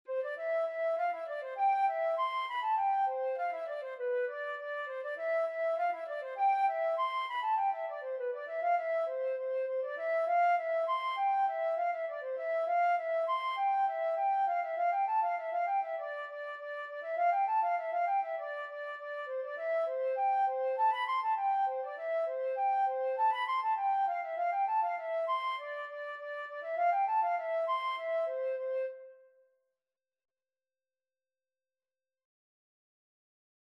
Flute version
2/4 (View more 2/4 Music)
B5-C7
Flute  (View more Easy Flute Music)